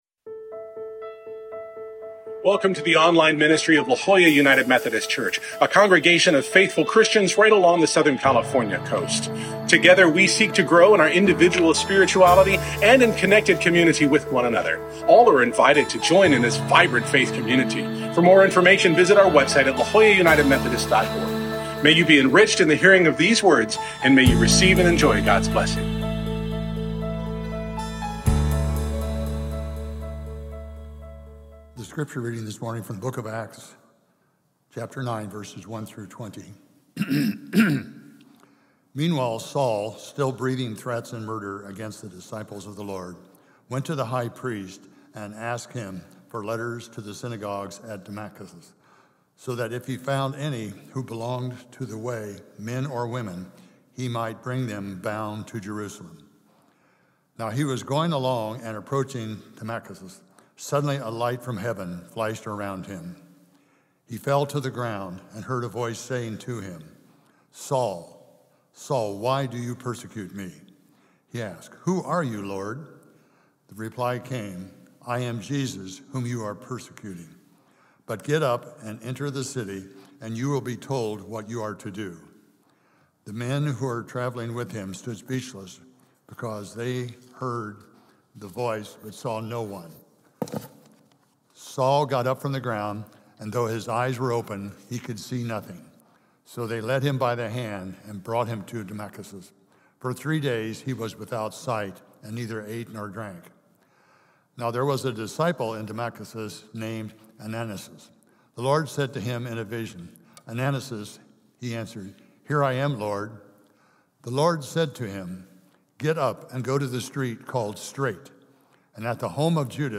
Order of Worship